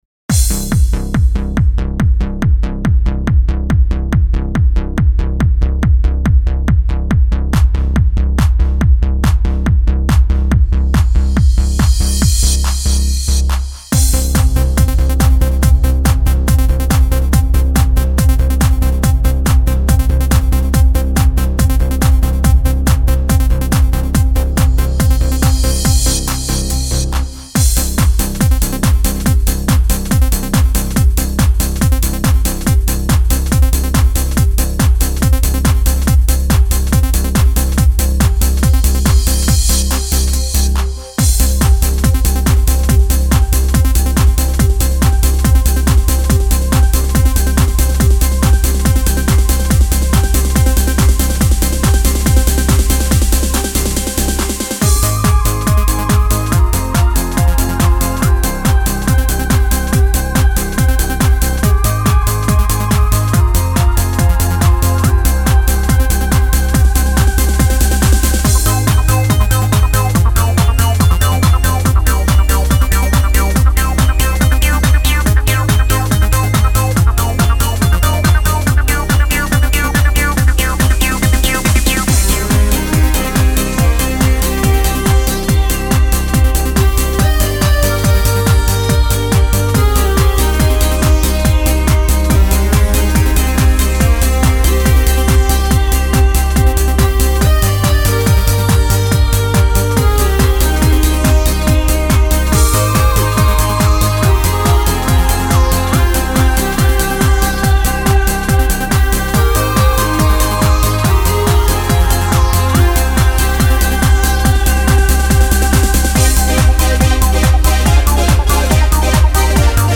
Жанр: Dance